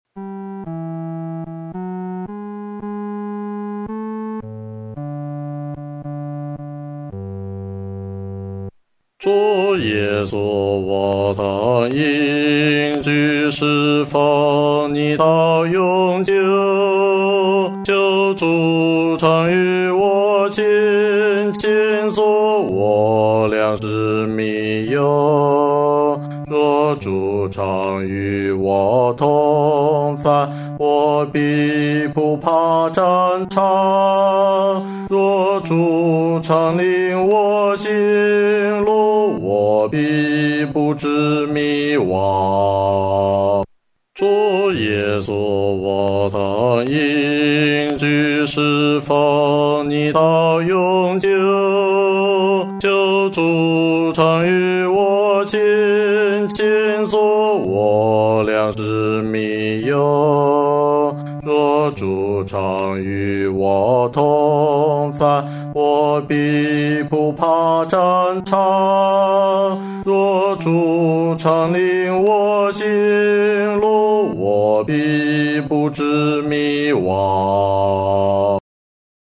合唱
男低